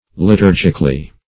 liturgically - definition of liturgically - synonyms, pronunciation, spelling from Free Dictionary Search Result for " liturgically" : The Collaborative International Dictionary of English v.0.48: Liturgically \Li*tur"gic*al*ly\, adv. In the manner of a liturgy.
liturgically.mp3